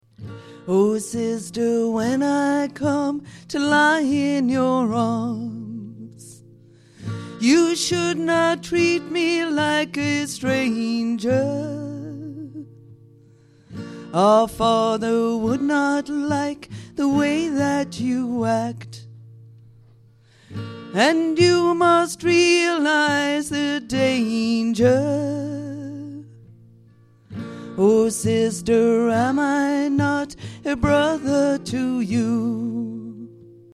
Ashington Folk Club - Spotlight 16 November 2006
guitar